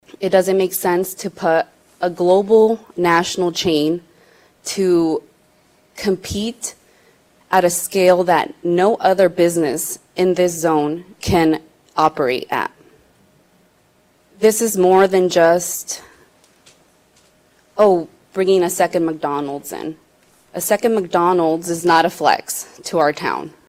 City Councilor Melisa Fonseca spoke out against this plan with the Planning and Zoning Committee and again last night.